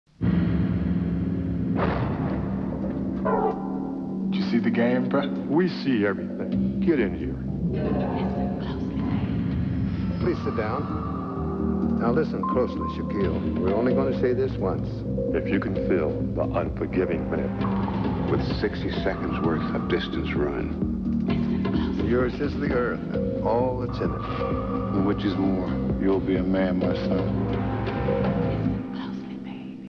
Shaq-Ad.mp3